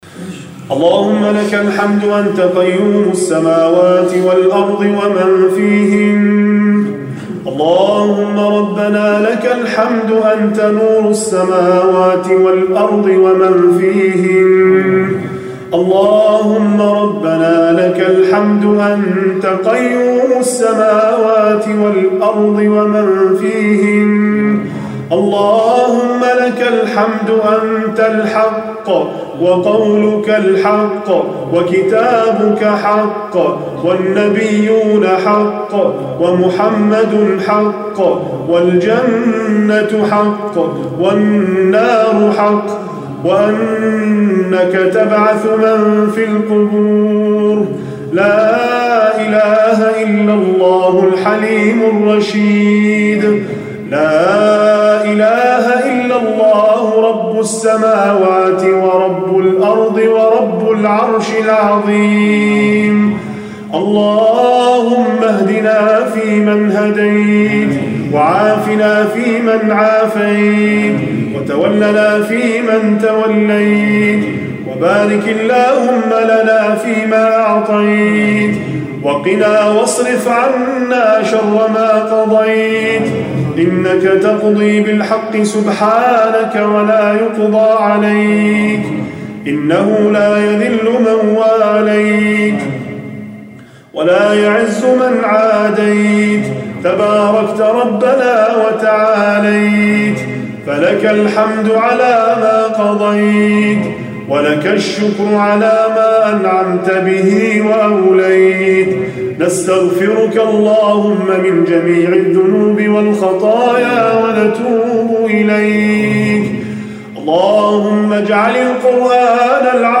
دعاء رمضان
تسجيل لدعاء خاشع ومؤثر من ليالي رمضان